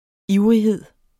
Udtale [ ˈiwʁiˌheðˀ ]